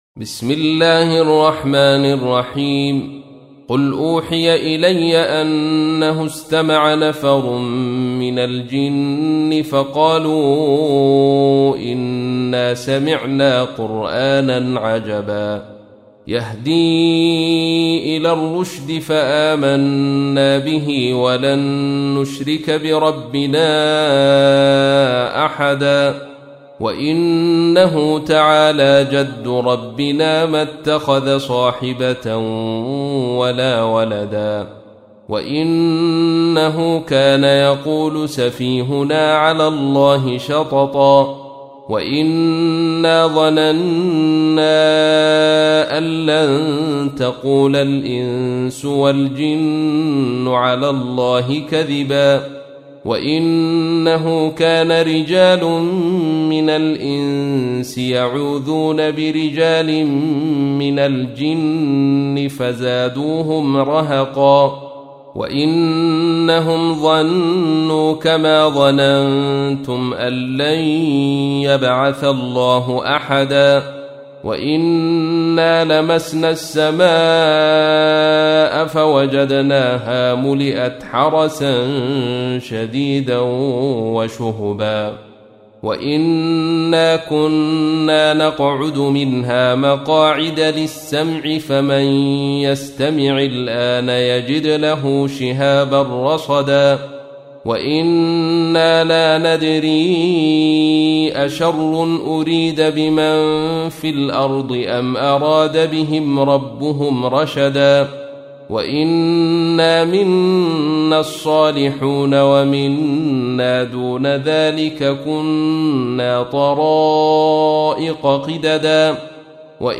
تحميل : 72. سورة الجن / القارئ عبد الرشيد صوفي / القرآن الكريم / موقع يا حسين